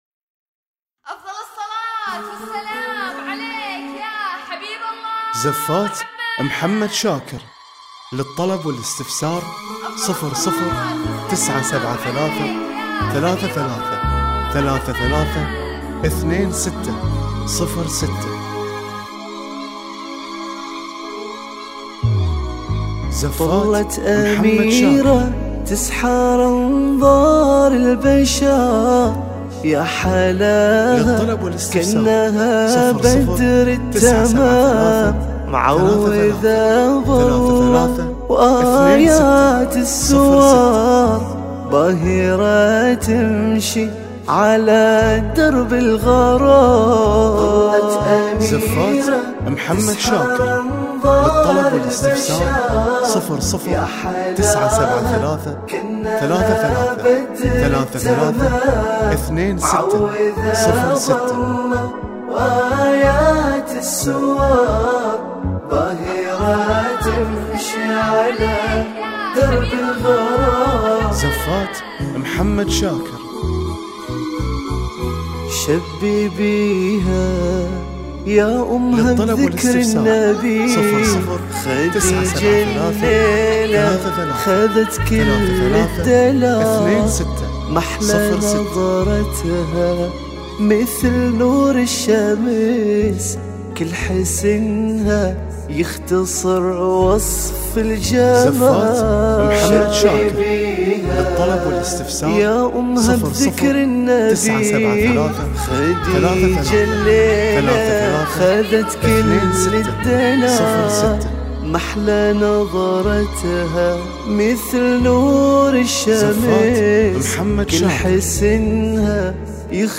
بالموسيقى
وتتوفر بالموسيقى وبدون موسيقى